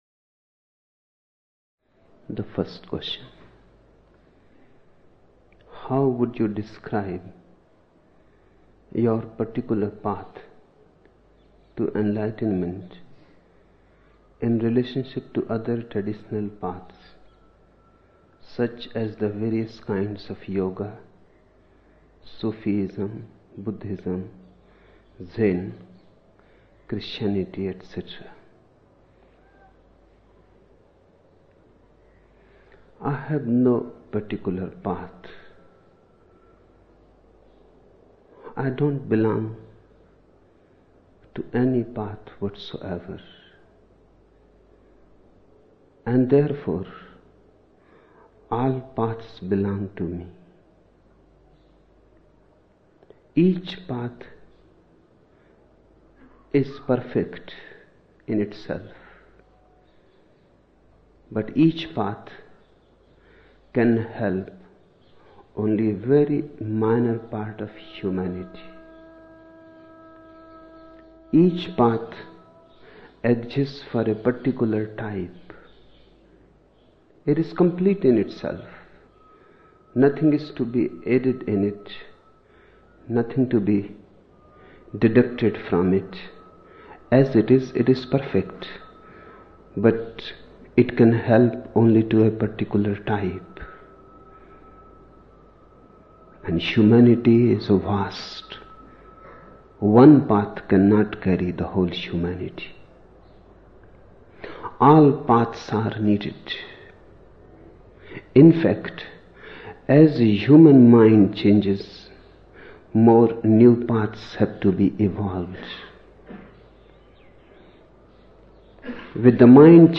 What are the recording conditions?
14 December 1975 morning in Buddha Hall, Poona, India